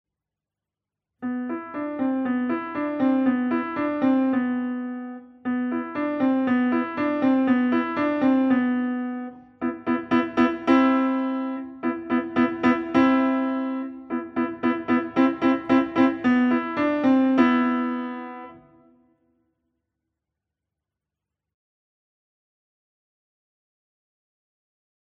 47 pfiffige Stücke für den Anfang am Klavier
Besetzung: Klavier